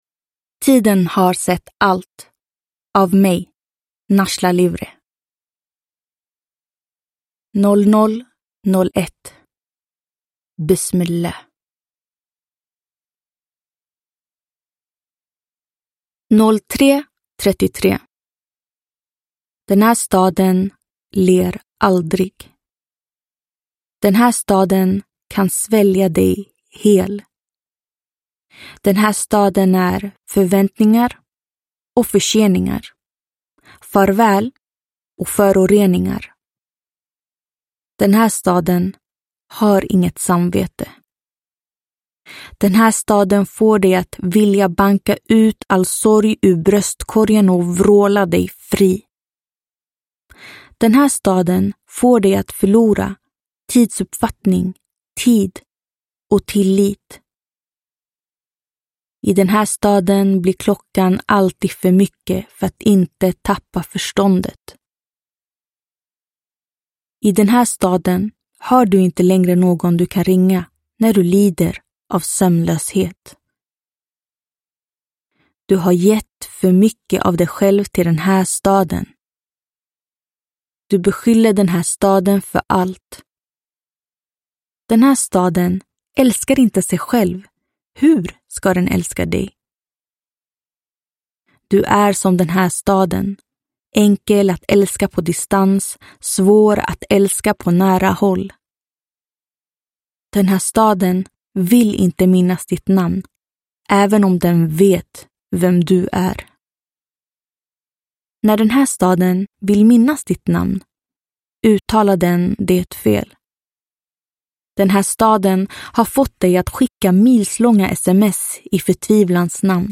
Tiden har sett allt – Ljudbok – Laddas ner